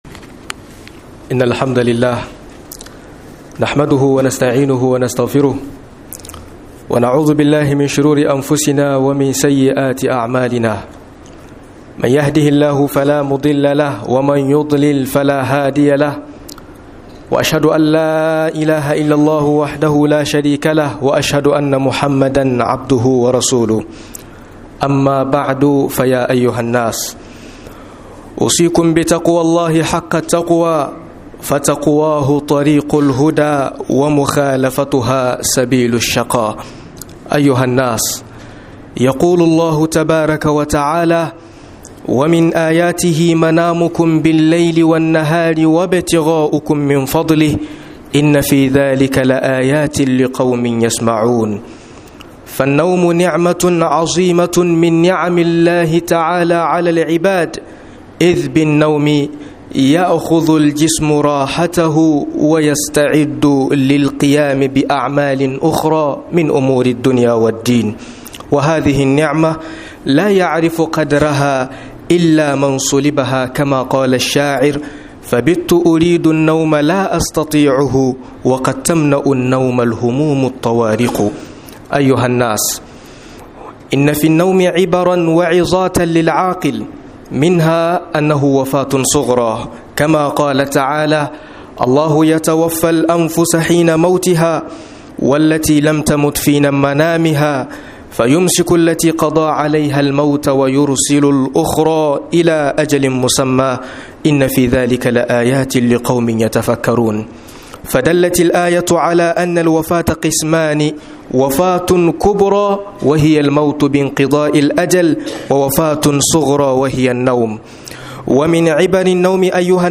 TAYA KWANA ZAI ZAMA IBADA A GAREKA - MUHADARA